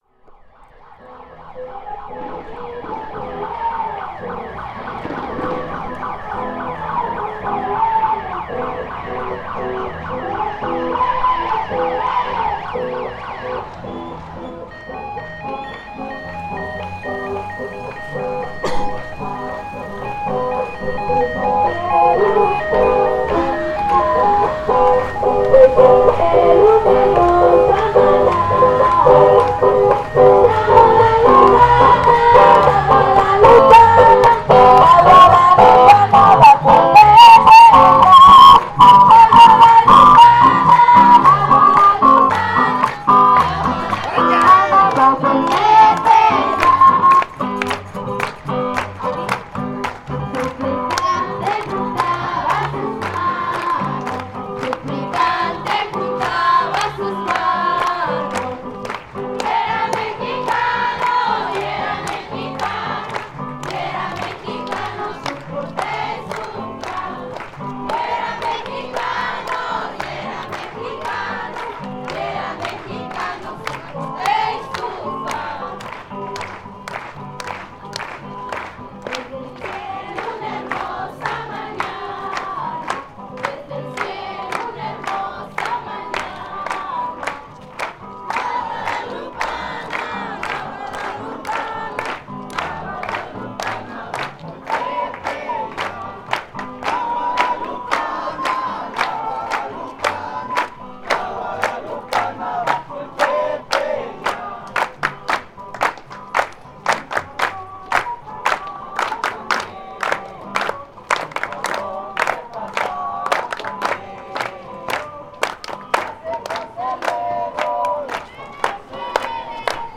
Los peregrinos cantan, se esfuerzan por dar lo mejor de sí en este día, lo único que les reprocho es el tiradero que dejan en las carreteras, la fe y las ganas ojalá sean para un mejor país, nuestro país se lo merece.
12 de diciembre de 2010 Lugar: Capilla de la Virgen de Guadalupe, Suchiapa, Chiapas, Mexico. Equipo: Alesis ProTrack.